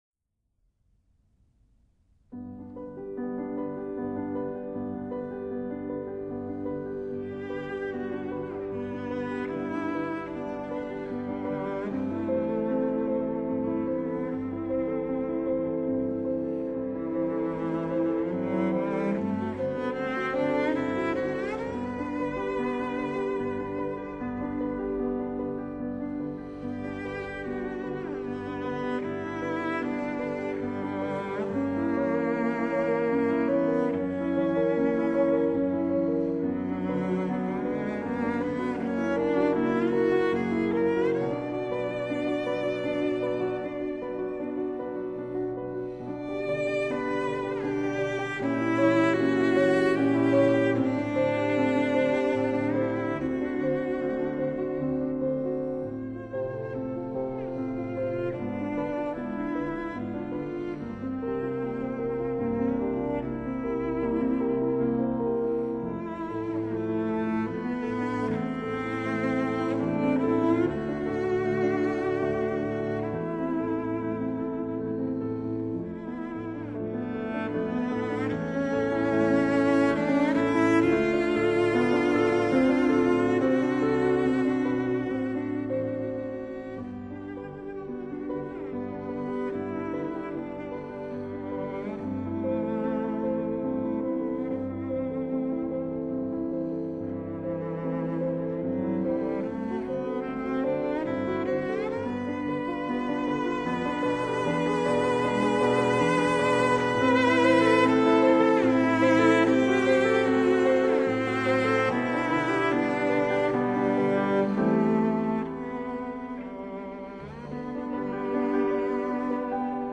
【大提琴慢板】
Genre: Classical/Instrumental